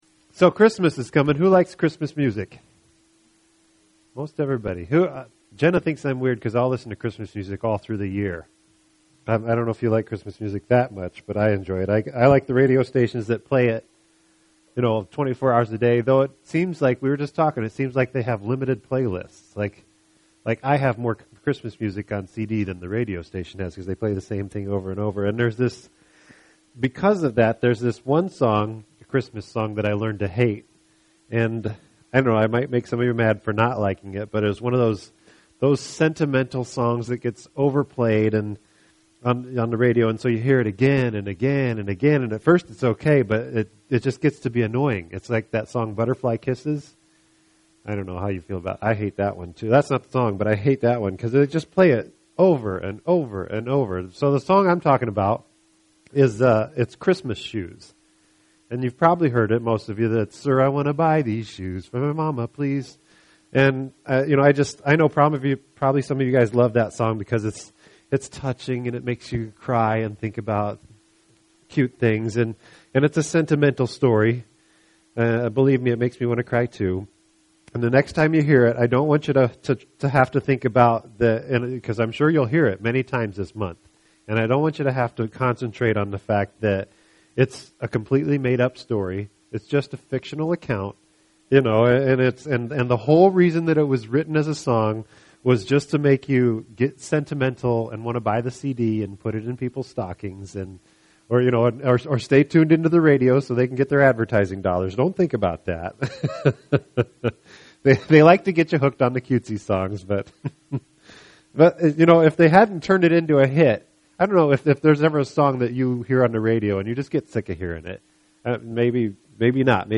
Podcast: Play in new window | Download (Duration: 39:26 — 18.1MB) This entry was posted on Saturday, November 3rd, 2012 at 1:23 am and is filed under Sermons .